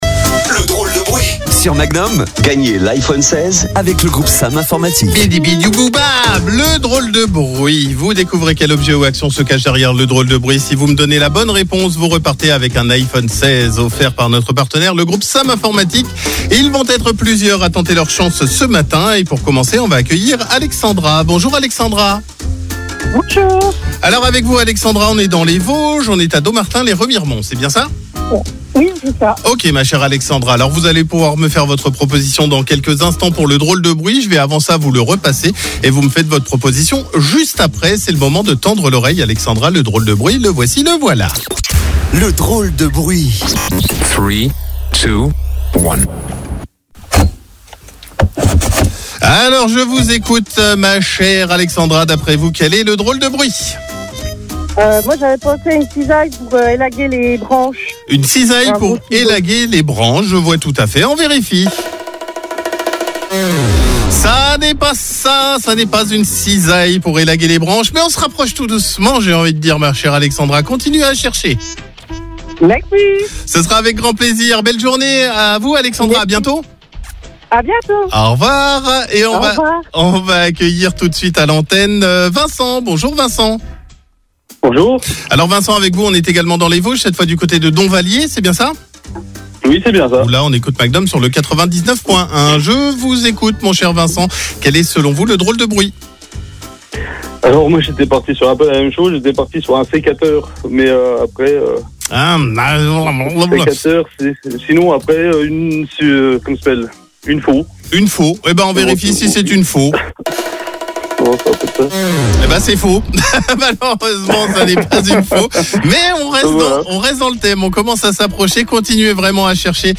Tentez de gagner un iPhone 16 en jouant au "Drôle de Bruit" sur Magnum La Radio ! Découvrez quel objet ou quelle action se cache derrière le DROLE DE BRUIT en écoutant CLUB MAGNUM entre 9h et 13h.